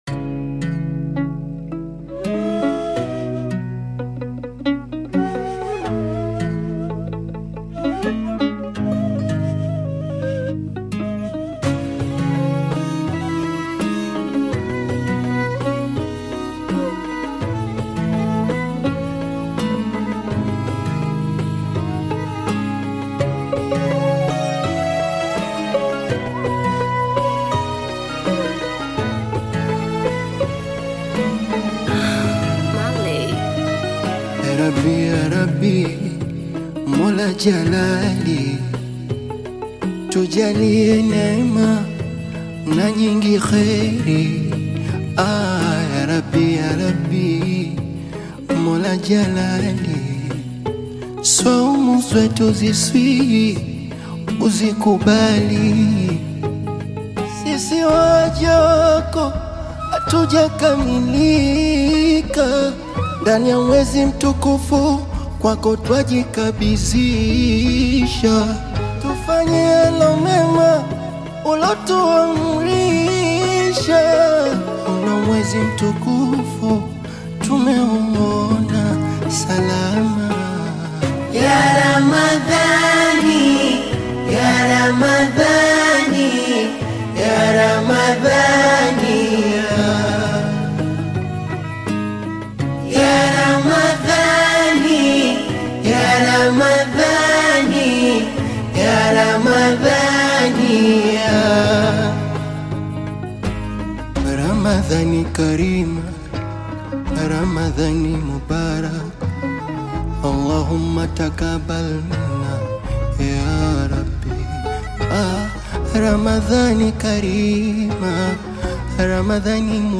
KASWIDA